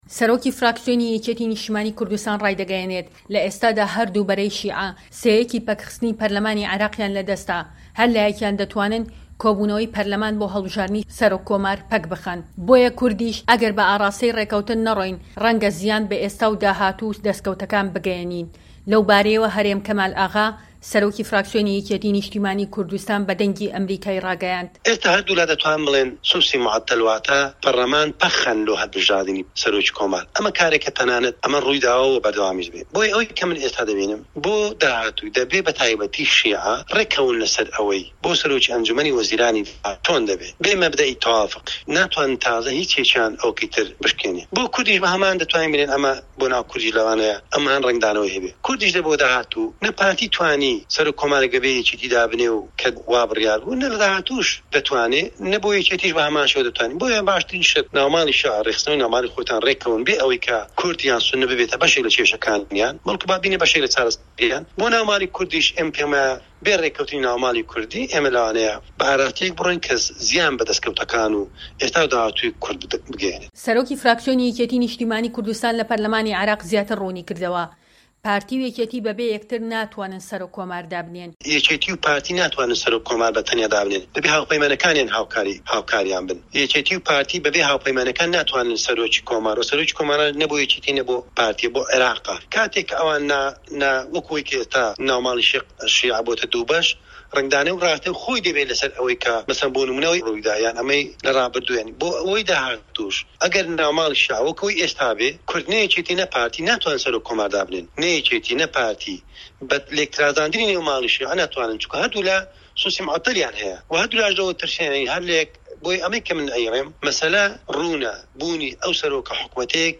وتووێژ لەگەڵ هەرێم کەمال ئاغا